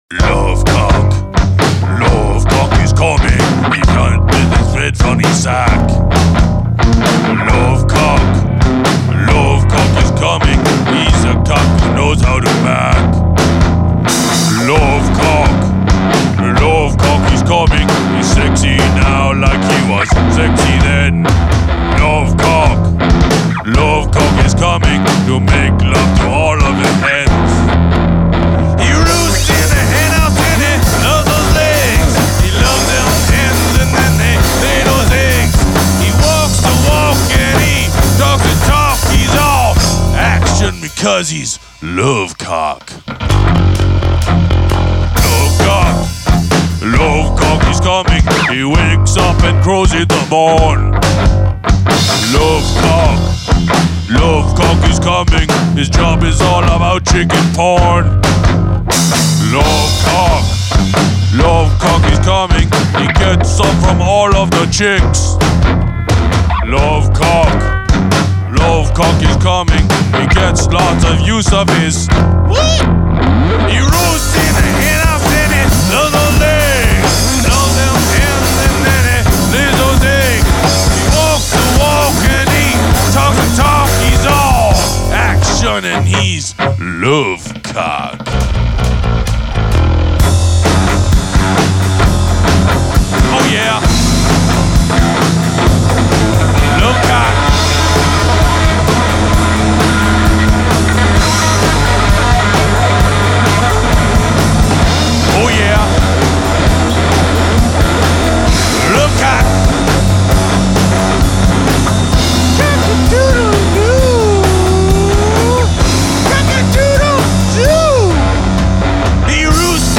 (live in the studio recording)